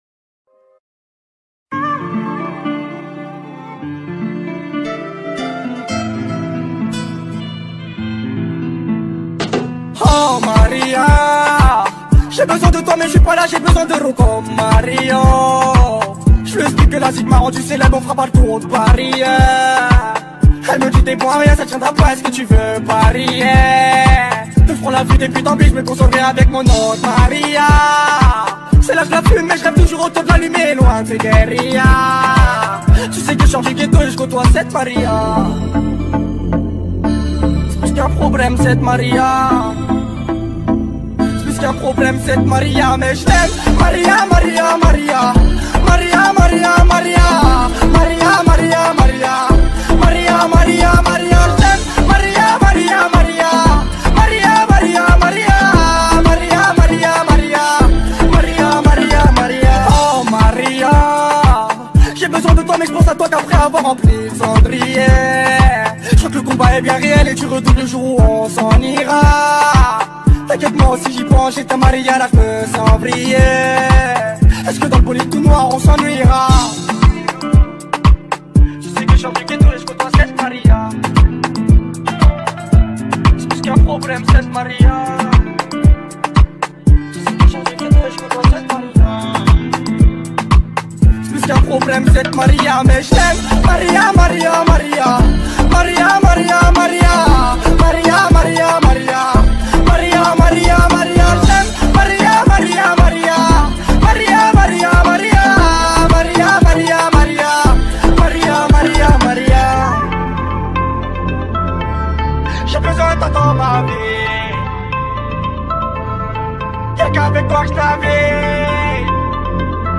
# Rap